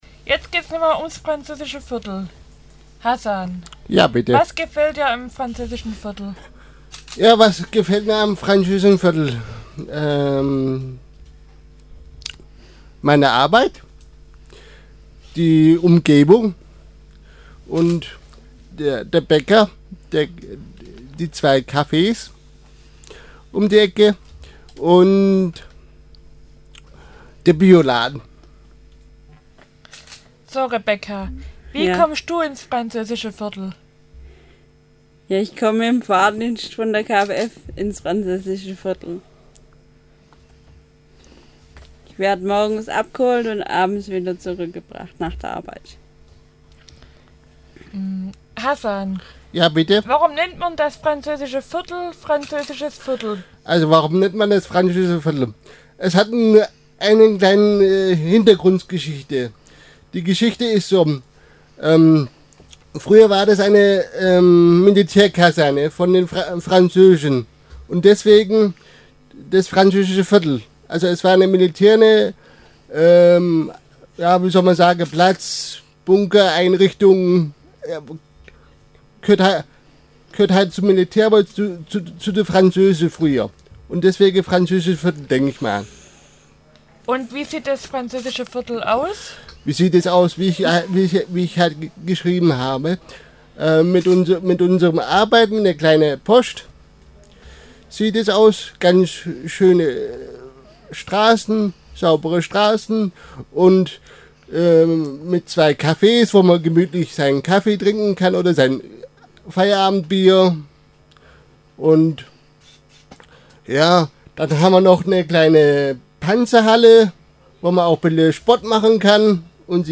47117_Diskussion_Franz_Viertel.mp3